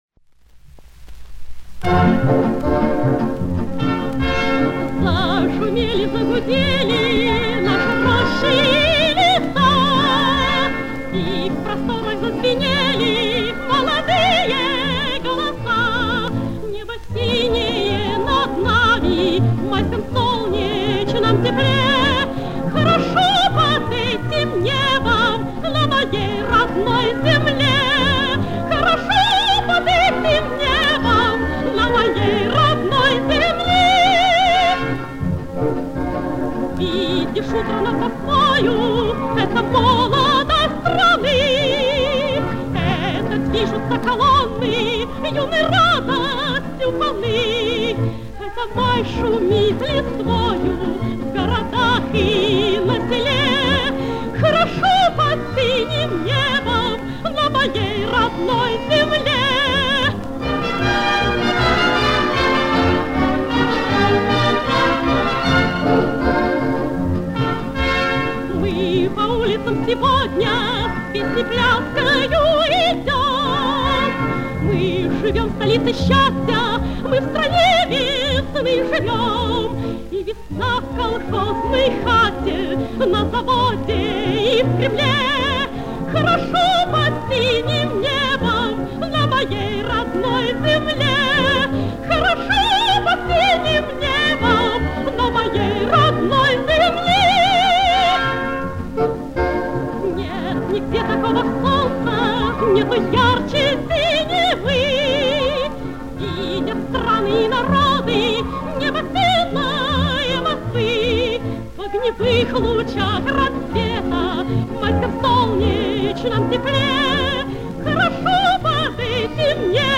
Источник грампластинка